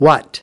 21. What /wɑːt/ : cái gì